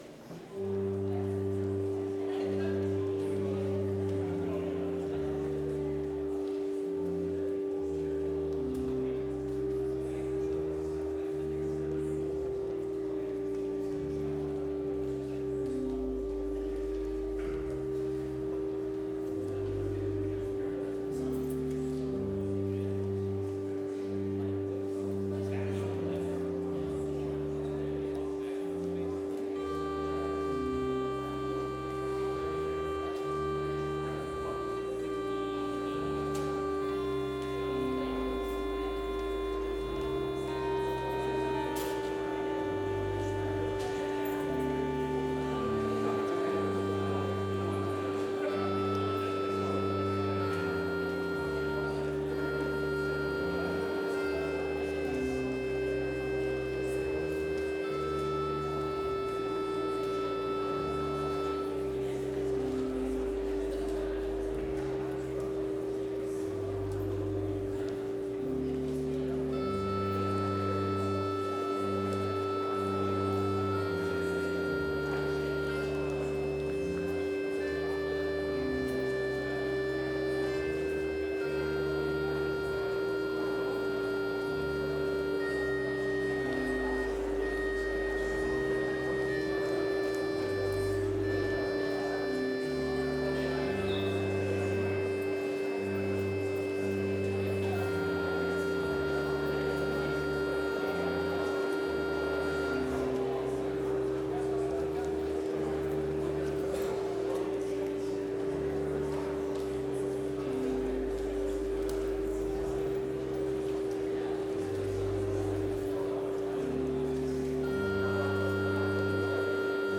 Complete service audio for Chapel - Monday, April 14, 2025